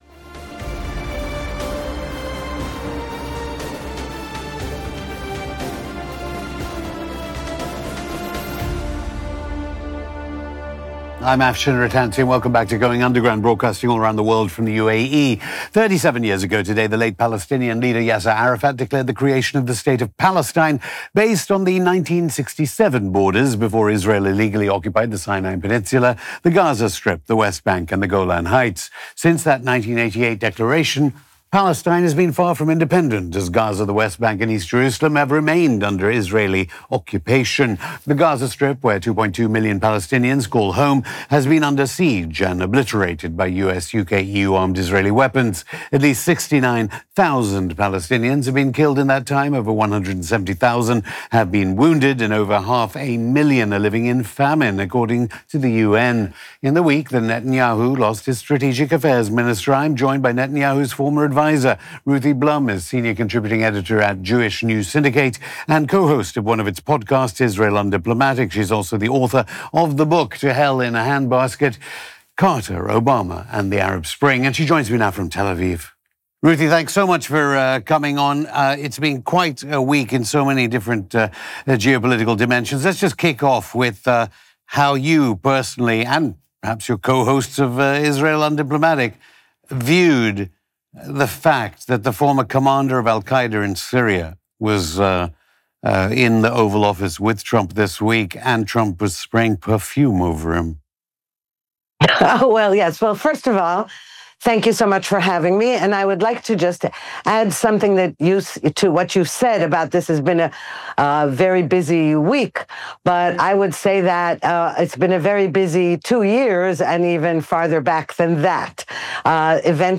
Going Underground Hosted by Afshin Rattansi ‘Time to WRAP UP the Israel Project?’